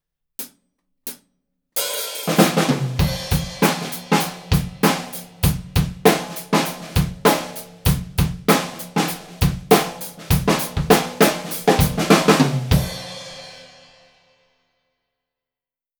すべて、EQはしていません。
④　ドラマー目線
低音が少なめですがドラムのアタック音が良く聞こえる様になりました。